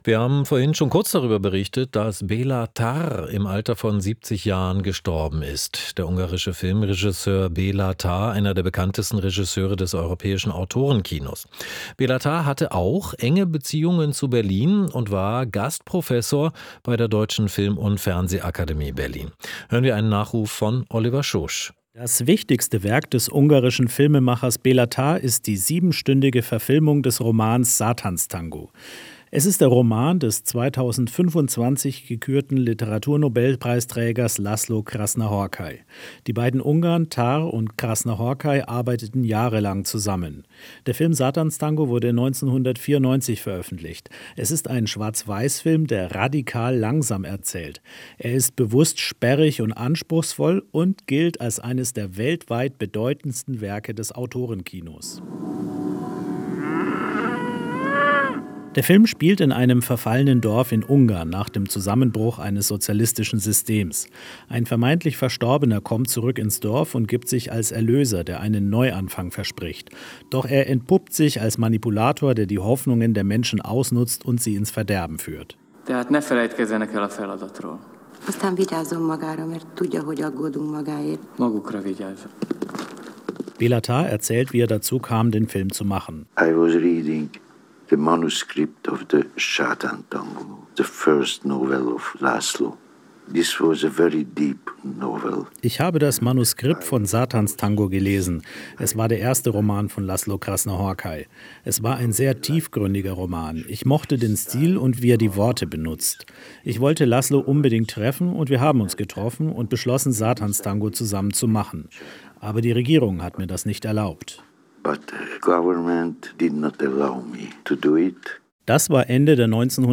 Ein Nachruf